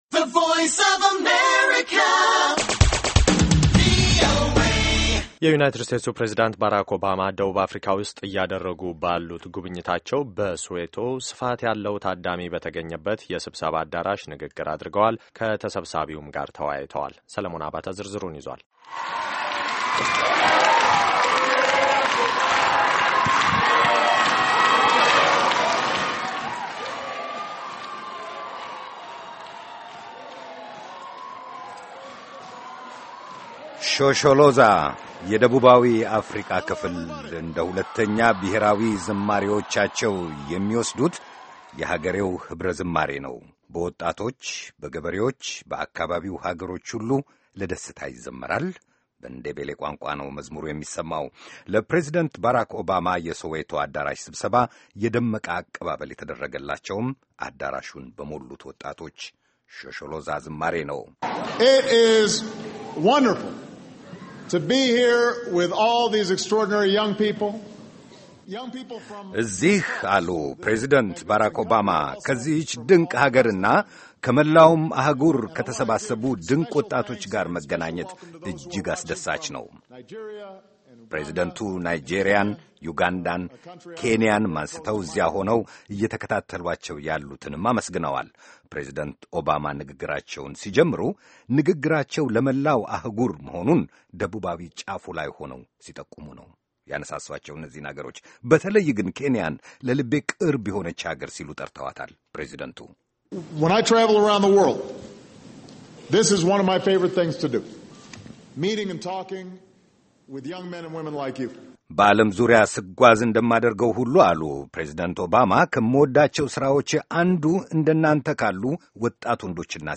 የዩናይትድ ስቴትስ ፕሬዚዳንት ባራክ ኦባማ ደቡብ አፍሪካ ውስጥ እያደረጉ ባሉት ጉብኝታቸው በሶዌቶ ስፋት ያለው ታዳሚ በተገኘበት የስብሰባ አዳራሽ ንግግር አድርገዋል፤ ከተሰብሳቢው ጋርም ተወያይተዋል፡፡